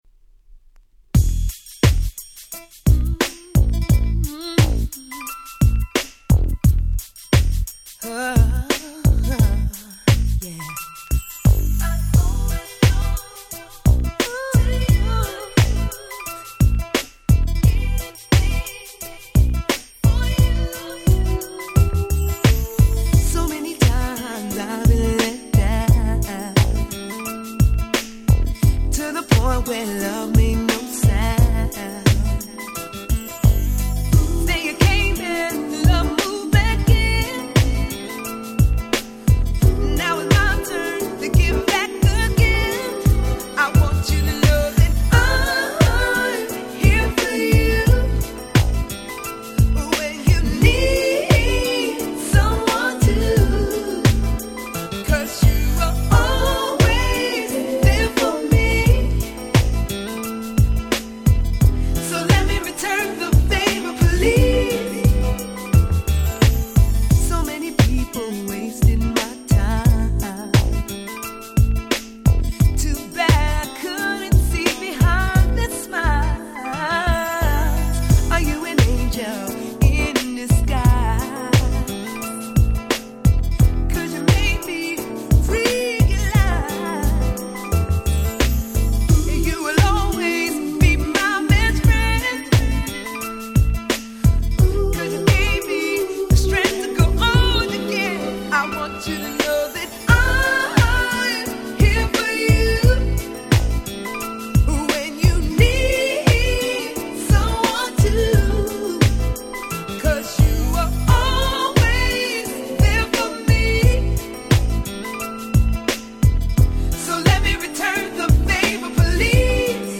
98' Smash Hit R&B !!
両面共にLauryn Hillを思わせる様なEarthyで温かいナンバー。
Neo Soul系がお好きなら尚のことストライクでしょう。